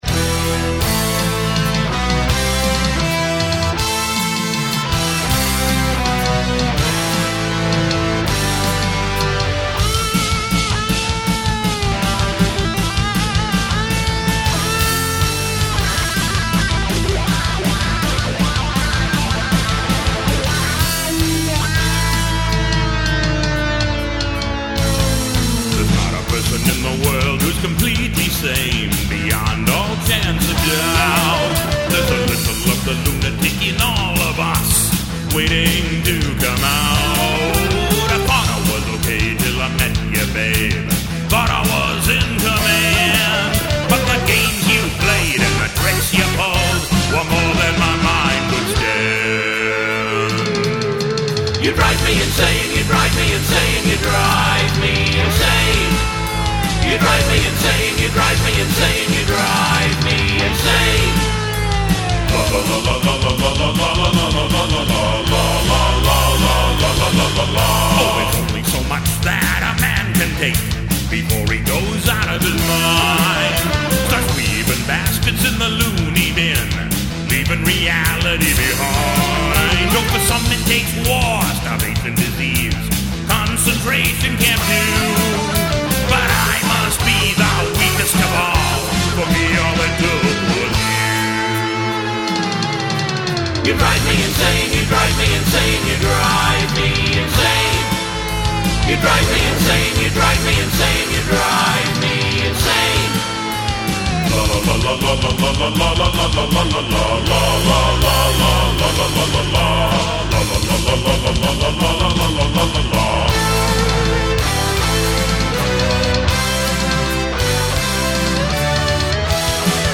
Musical Assistance